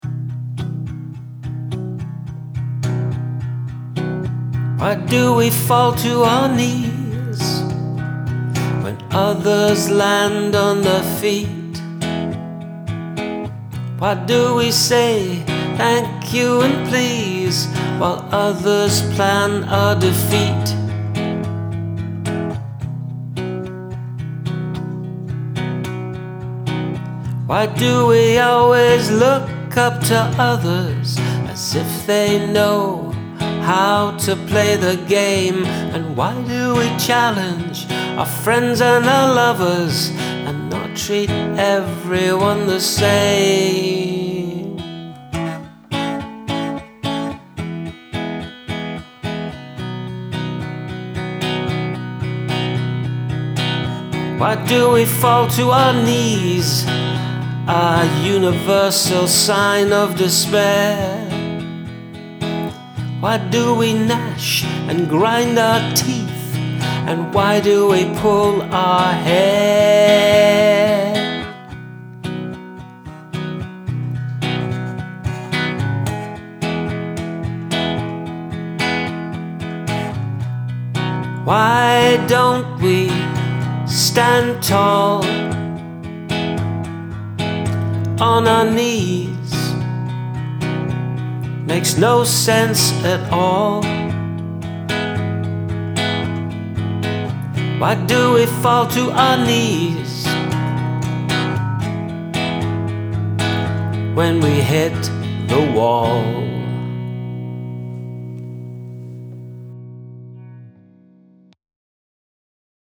Good strong demo.